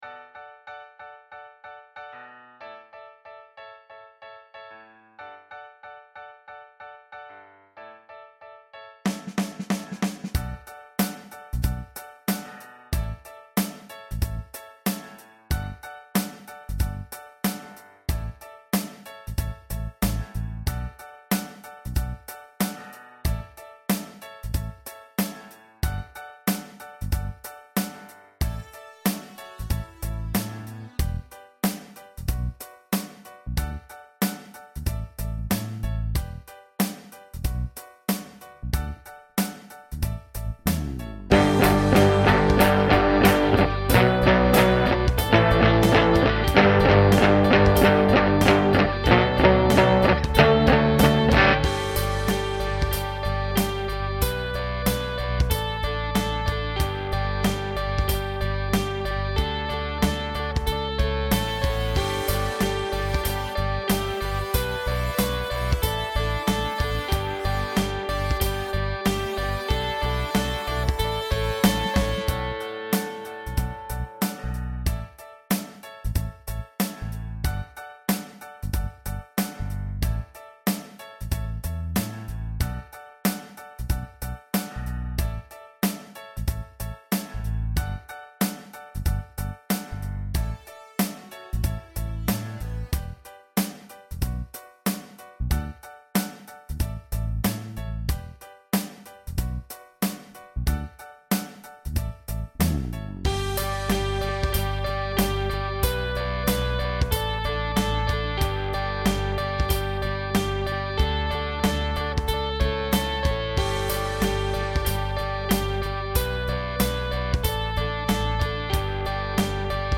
Guitar ready or not draft